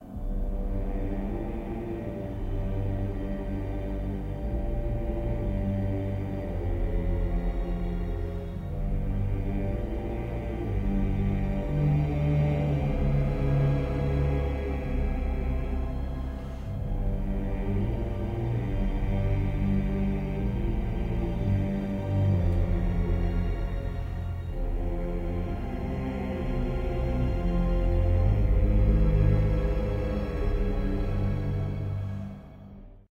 Film score
Key G minor
Time signature 4/4